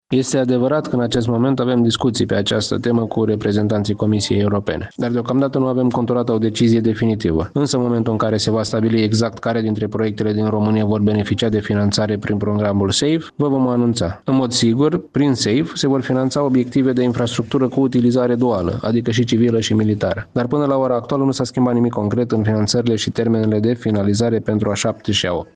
Până la un răspuns favorabil din partea Comisiei, termenele de finalizare a segmentelor de autostrăzi A7 și A8, aflate în execuție, rămân neschimbate, a precizat Ministrul Transporturilor, Ciprian Șerban, într-o intervenție pentru Radio Iași: